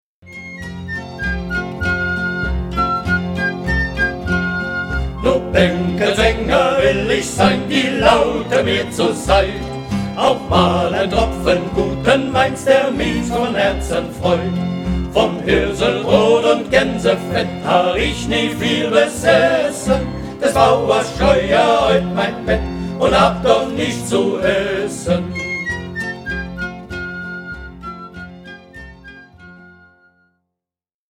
(Gruppenfassung)